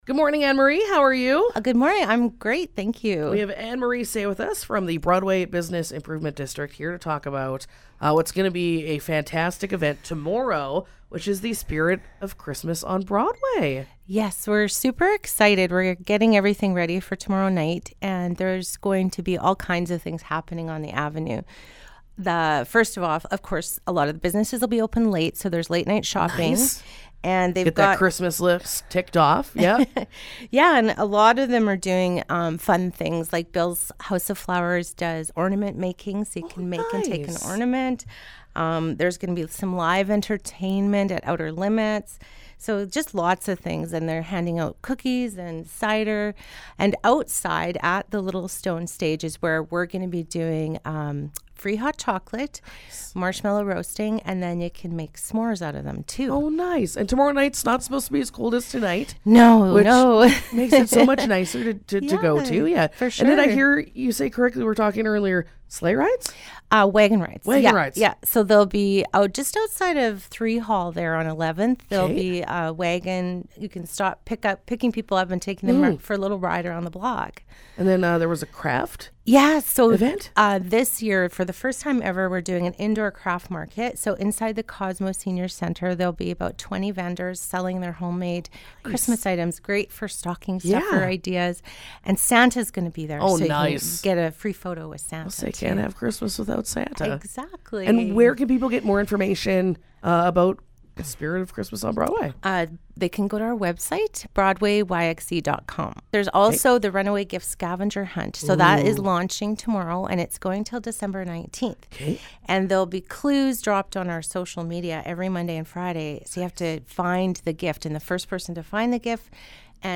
Interview: Spirit of Christmas on Broadway